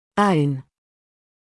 [əun][оун]свой, собственный; владеть